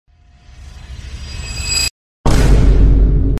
Riser Sound Effects MP3 Download Free - Quick Sounds